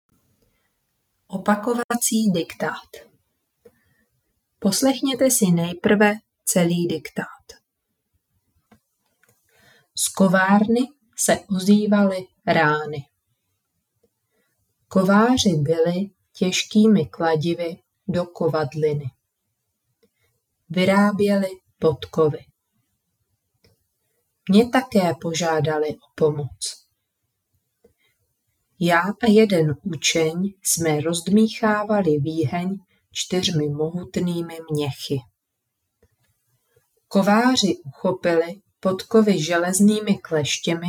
AudioDiktáty 5. ročník Opakování
Diktáty odpovídají osnovám, zvuky jsou čisté a srozumitelné, tempo vhodné pro děti.